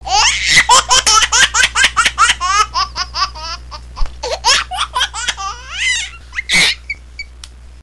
効果音 赤ちゃんMp3